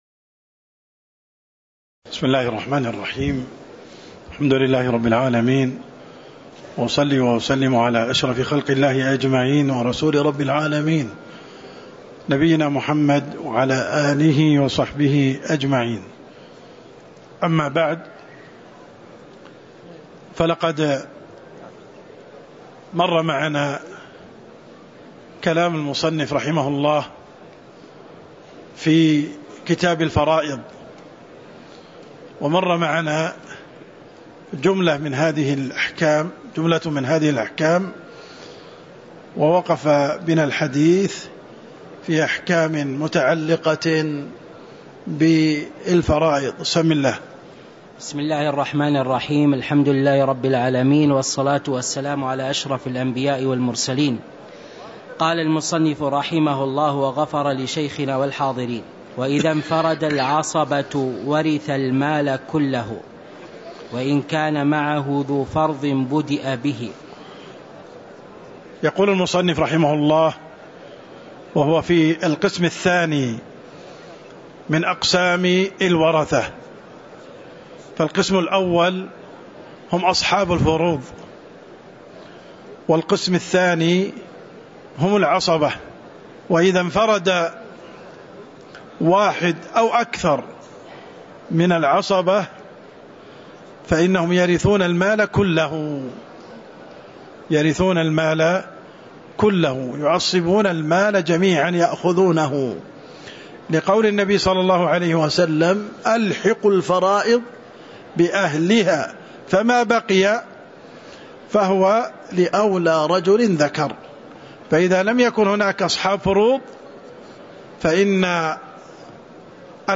تاريخ النشر ٢١ ربيع الأول ١٤٤٤ هـ المكان: المسجد النبوي الشيخ: عبدالرحمن السند عبدالرحمن السند قوله: باب العصبات (02)